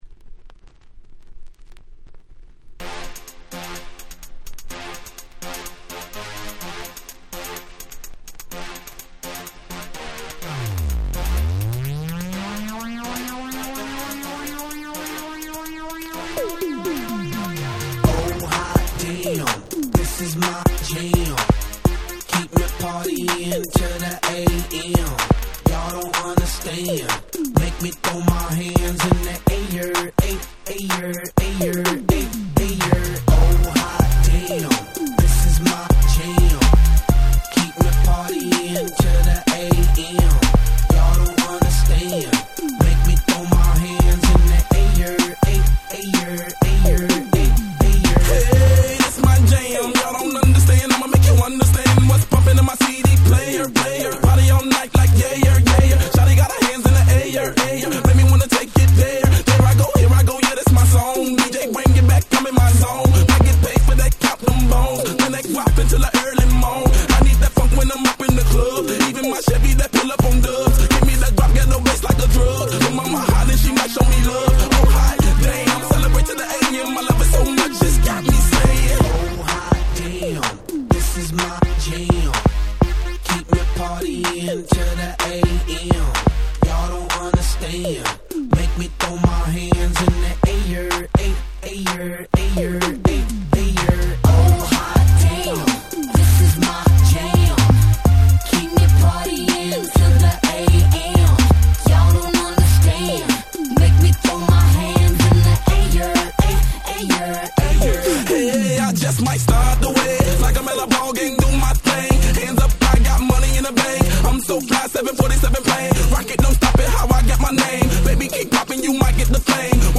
08' Super Hit Hip Hop !!